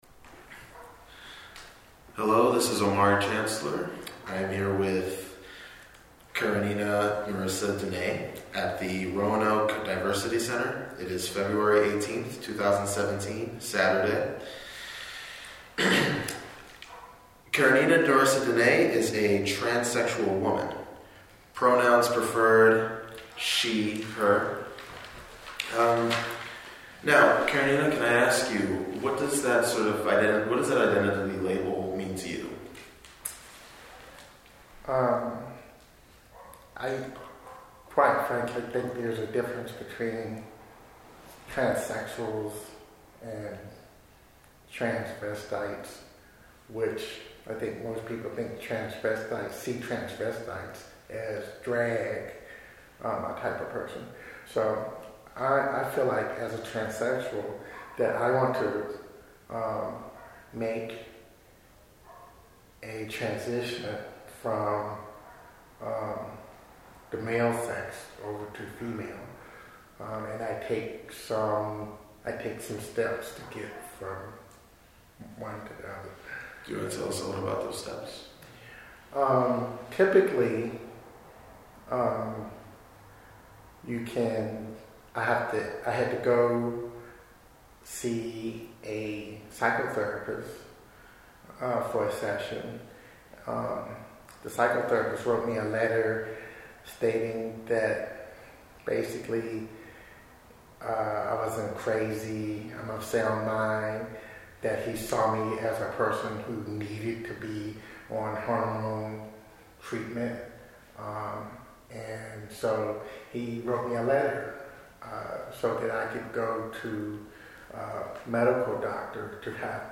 Oral History Interview
Location: Roanoke Diversity Center